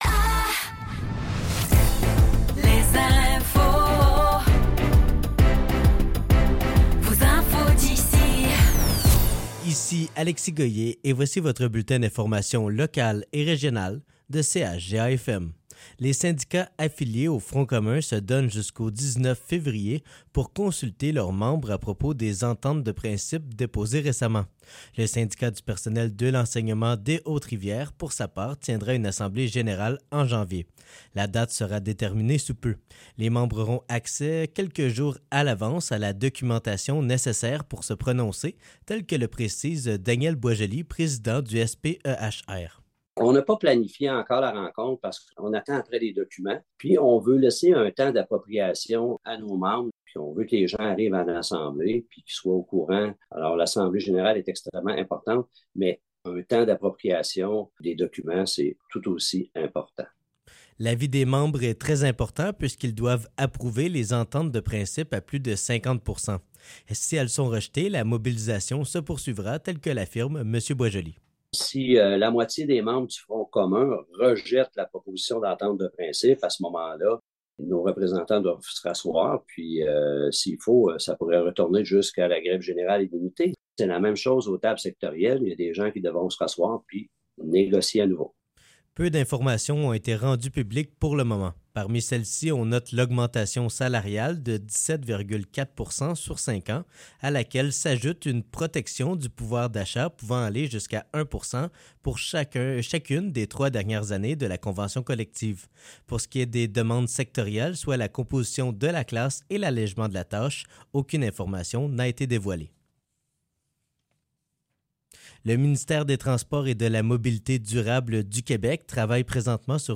Nouvelles locales - 8 janvier 2024 - 15 h